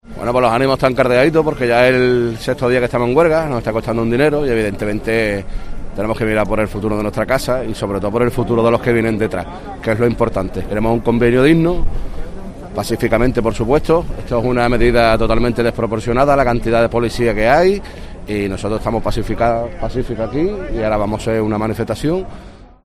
Trabajadores en la huelga del metal